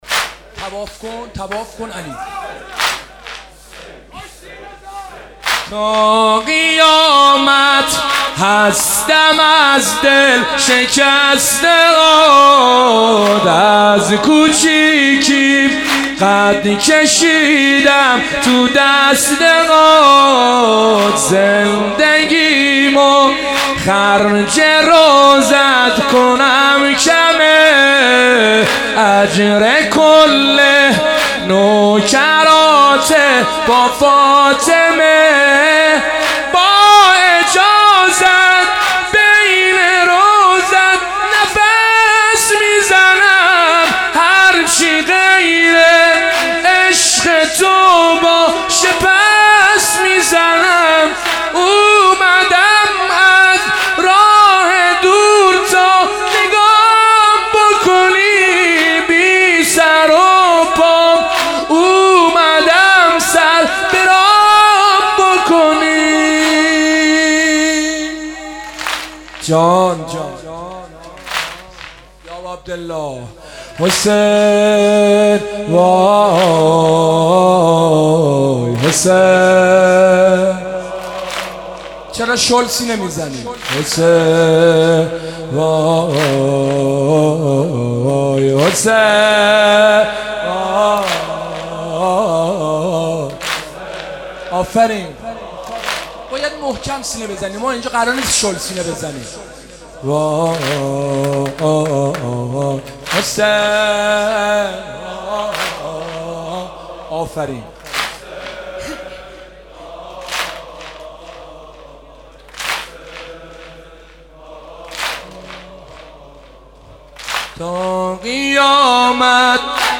مناسبت : شب ششم محرم